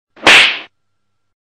slap-sound-effect-free_tXPEW14.mp3